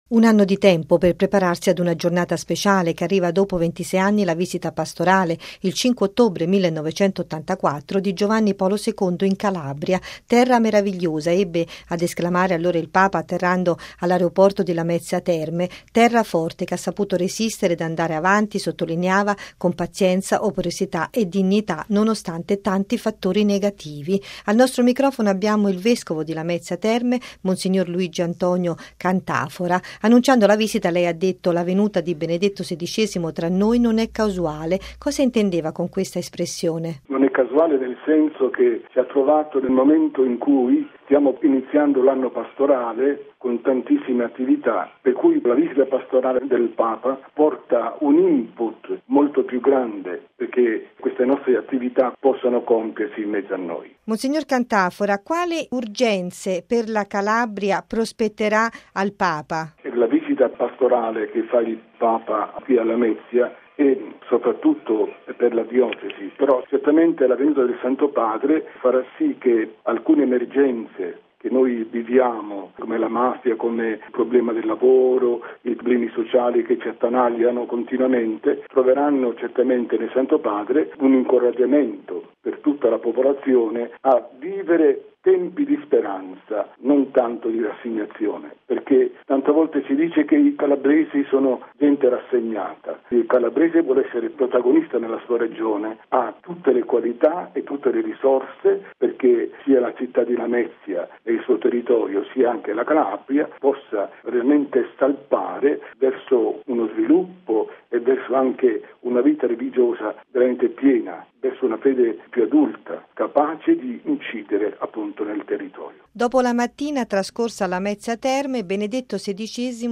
Al nostro microfono, il vescovo di Lamezia Terme, mons. Luigi Antonio Cantafora.